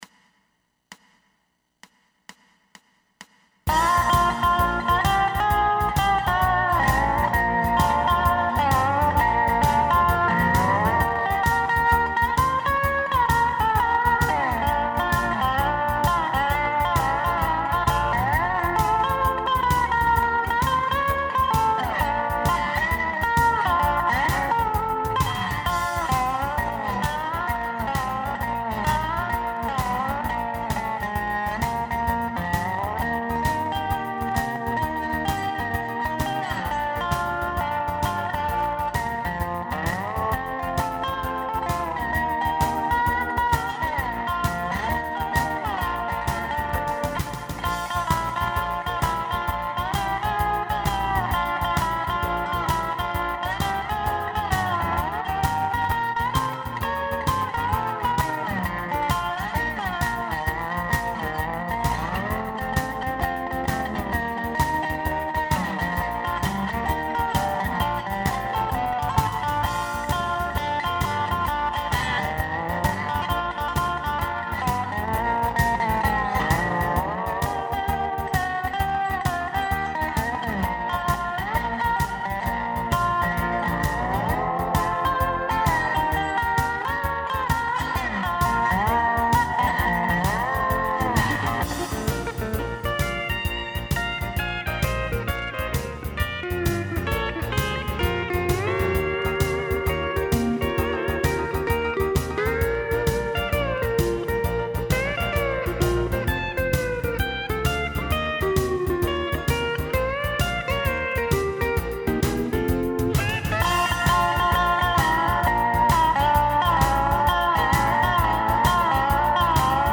A blues improvisation
Dobro
Pedal Steel Guitar